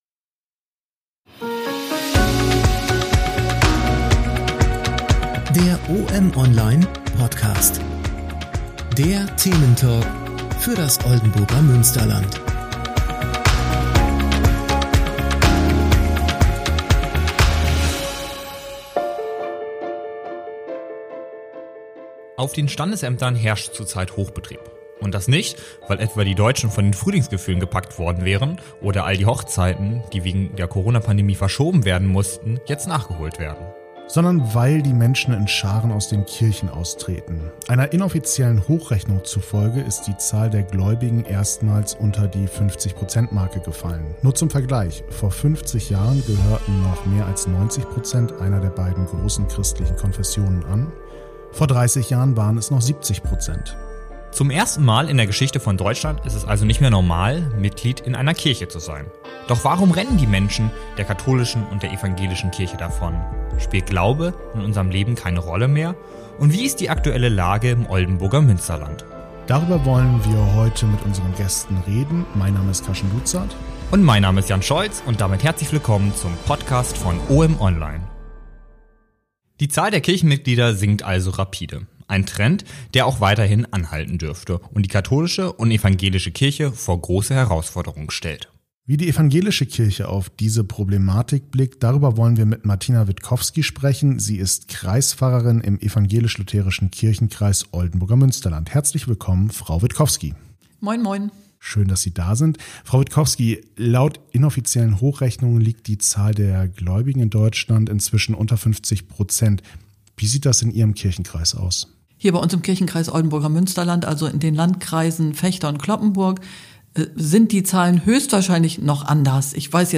Doch warum rennen der katholischen, wie auch der evangelischen Kirche die Mitglieder davon? Welche Rolle spielt der Glaube noch? Und wie sieht die Situation im Oldenburger Münsterland aus? Über diese und weitere Fragen unterhalten sich die Moderatoren